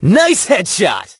hotshot_kill_06.ogg